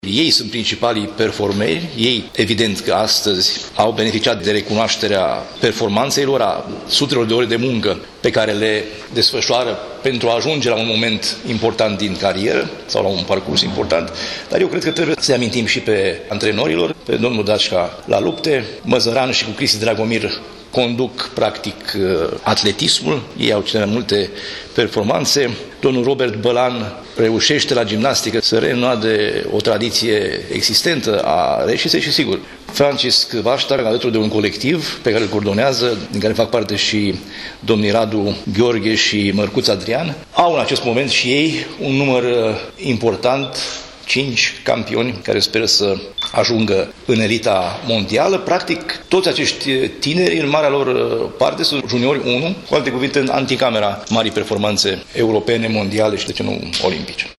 Festivitatea a avut loc în sala de şedinţe a Palatului Administrativ.
Preşedintele Consiliului Judeţean a apreciat şi implicarea antrenorilor în obţinerea rezultatelor. Despre cei care stau în spatele performanţei sportivilor, Sorin Frunzăverde a declarat: